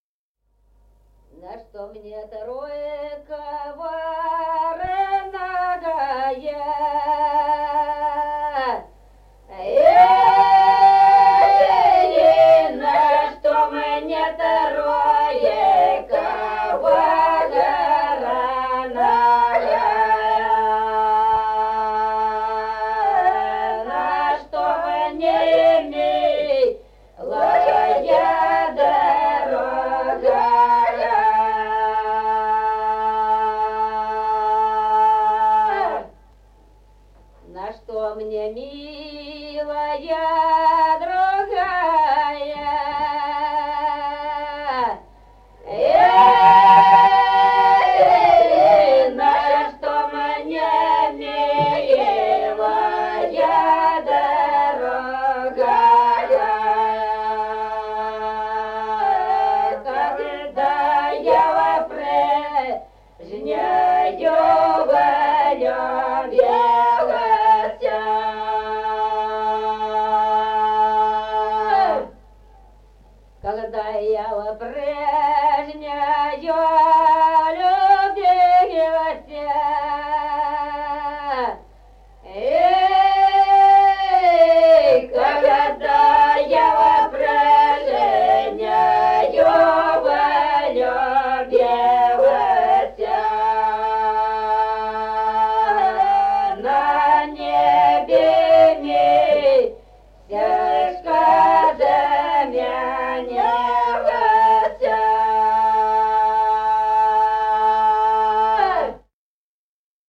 Песни села Остроглядово На что мне тройка вороная.
Песни села Остроглядово в записях 1950-х годов